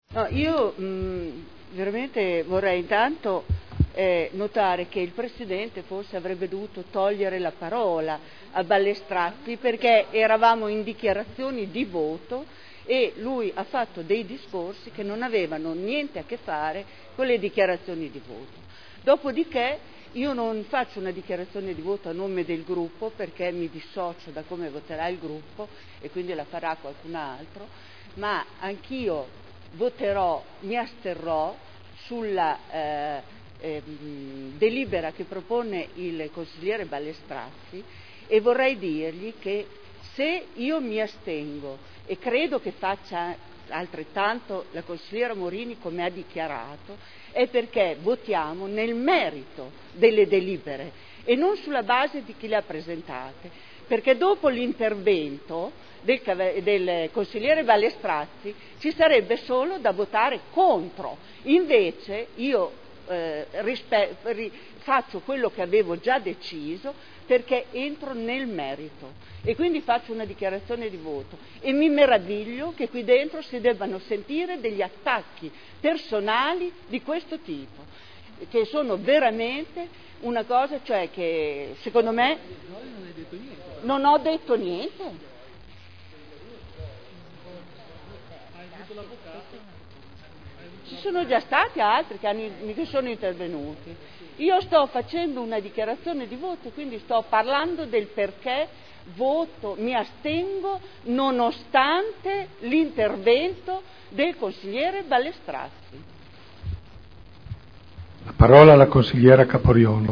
Franca Gorrieri — Sito Audio Consiglio Comunale
Dichiarazione di voto su delibera: Acqua bene comune universale, pubblico, indisponibile, che appartiene a tutti (Proposta di deliberazione del consigliere Ballestrazzi) (Commissione consiliare del 9 luglio 2010 – parere negativo) (Relatore consigliere Ballestrazzi)